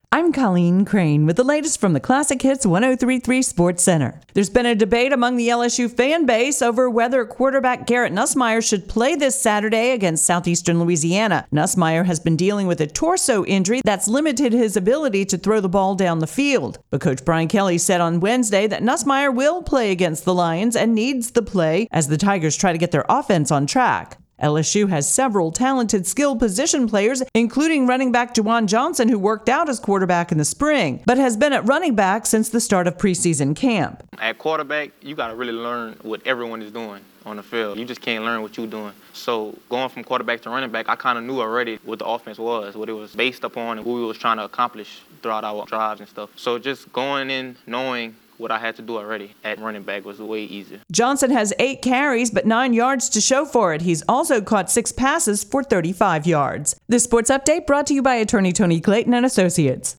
WRQQ AM sportscast.mp3